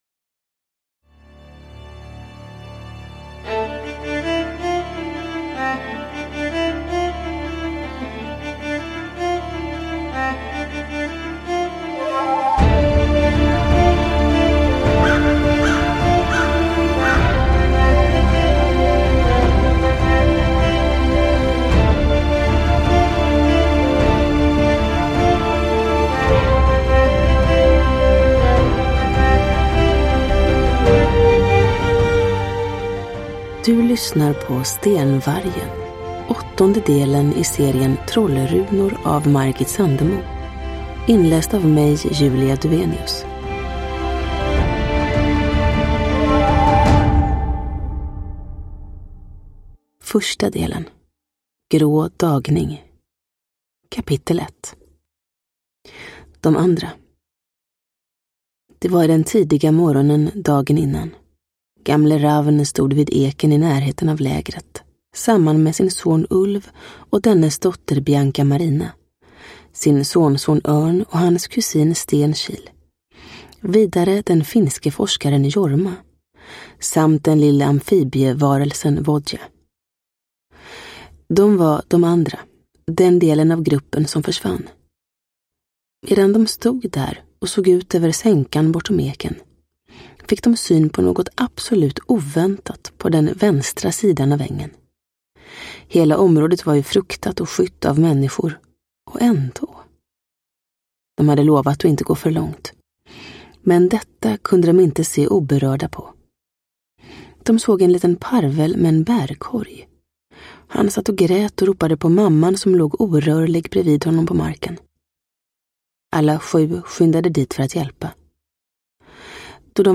Stenvargen – Ljudbok – Laddas ner